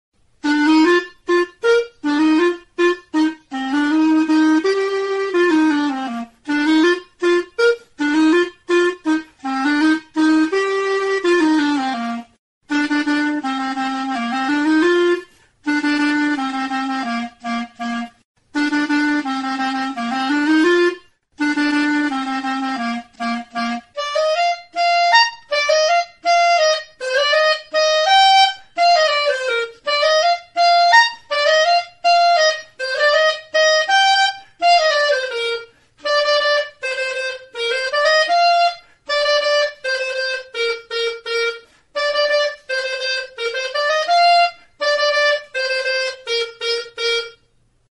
Aerophones -> Reeds -> Single fixed (clarinet)
Recorded with this music instrument.